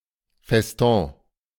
Das Feston (IPA: [fɛsˈtɔ̃ː][1][2],